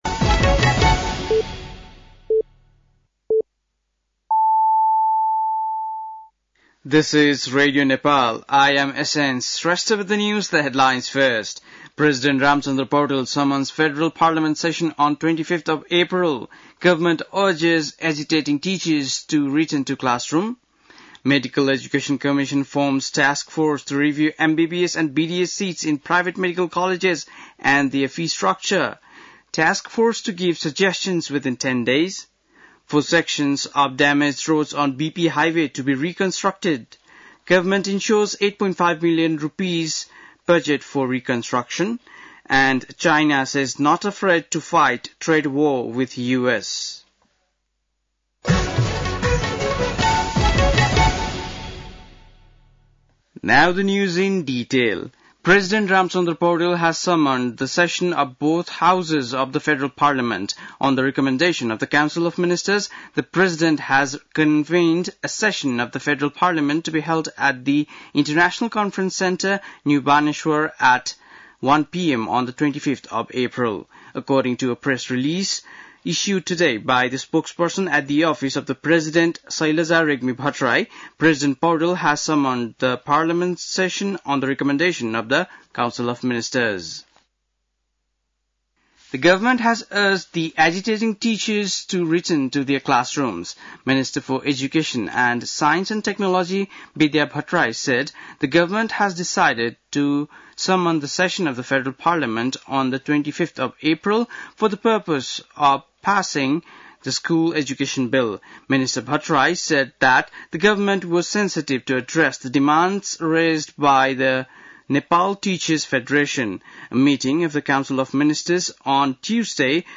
बेलुकी ८ बजेको अङ्ग्रेजी समाचार : ३ वैशाख , २०८२
8-pm-english-news-1.mp3